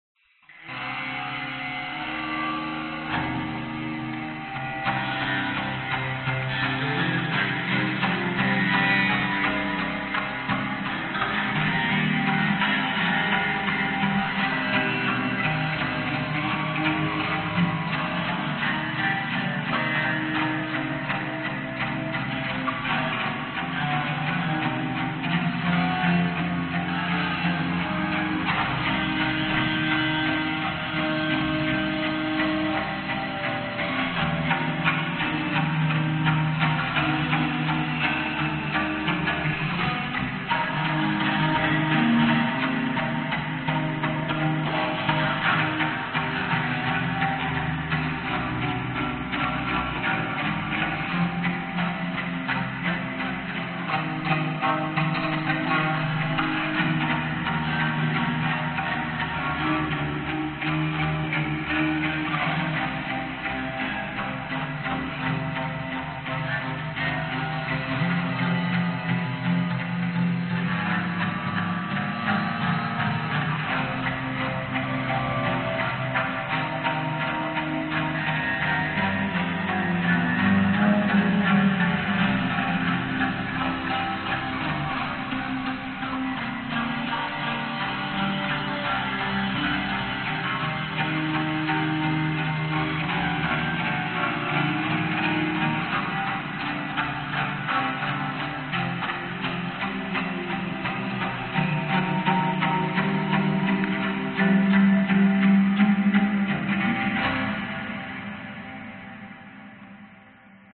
动作打击乐合奏快板 " 动作打击乐合奏快板3 (170 BPM)
描述：快速打击行动或戏剧电影。 170 BPM
声道立体声